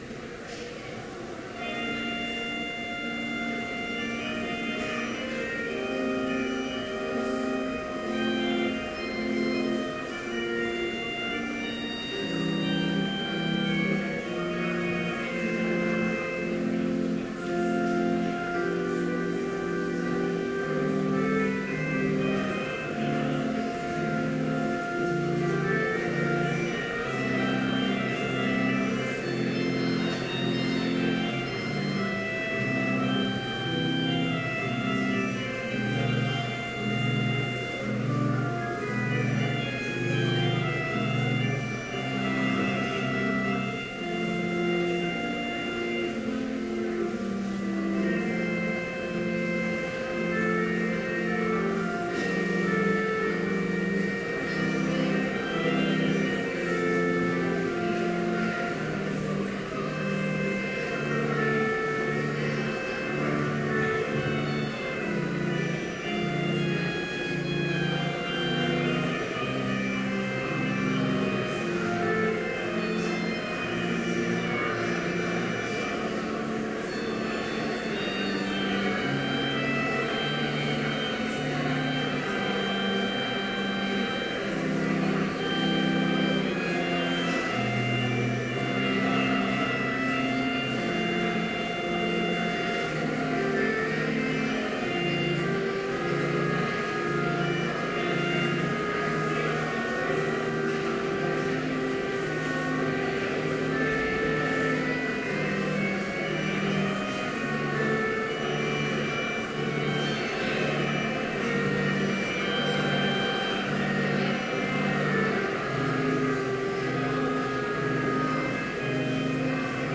Complete service audio for Chapel - September 22, 2014